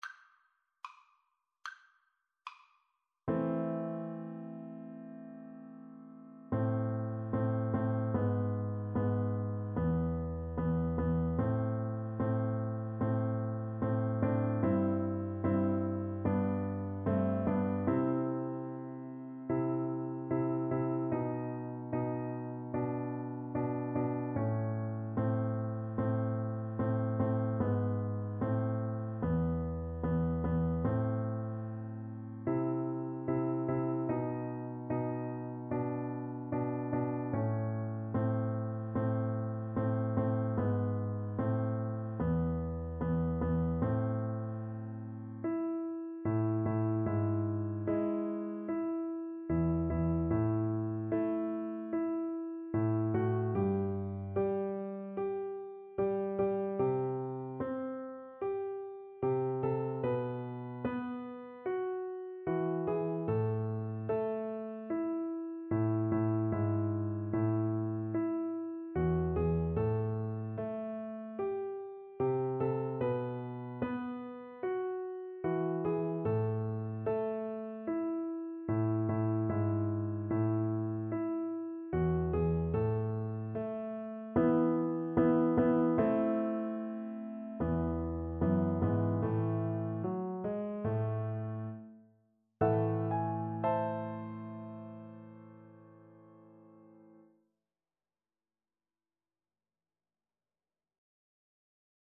Free Sheet music for Piano Four Hands (Piano Duet)
2/4 (View more 2/4 Music)
Classical (View more Classical Piano Duet Music)